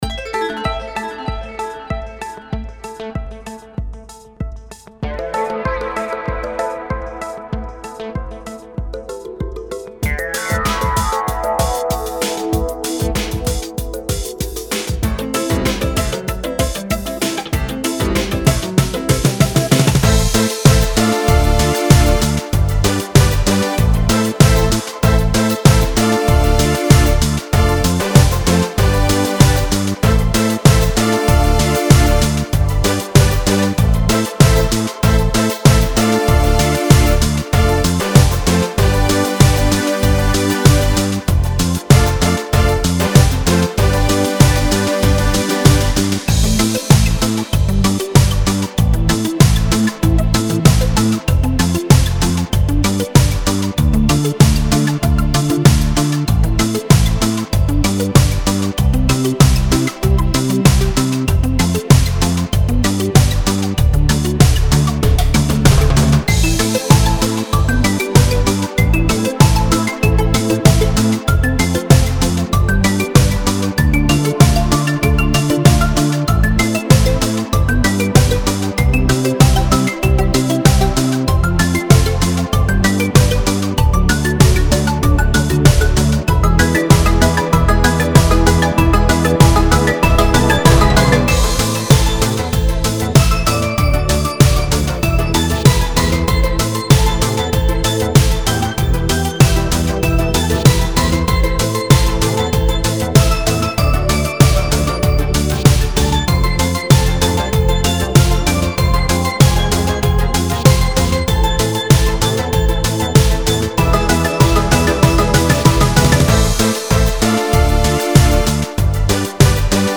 mp3 Скачать минус Похожие Смотри ещё